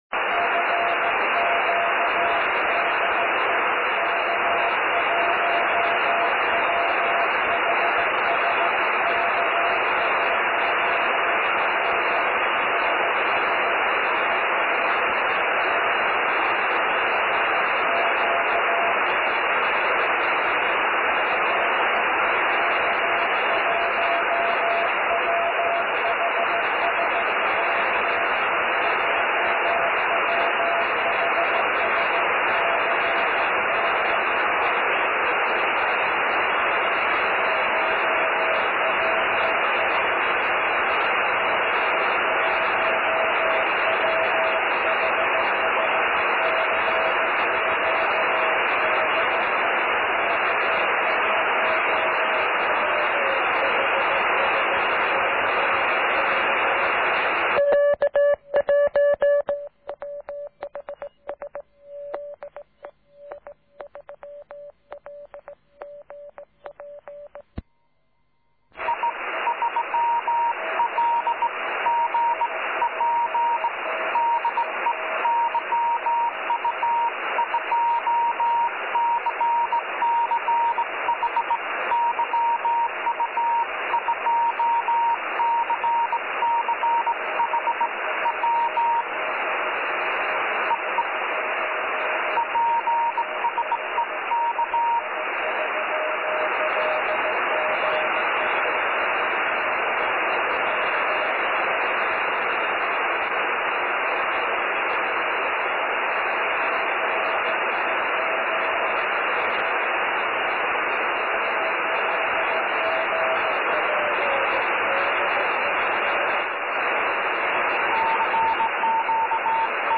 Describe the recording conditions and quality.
On that Sunday afternoon, at the end of international September contest, my antenna was randomly pointed to the moon at its set position (at that moment, I was not aware of this circumstance).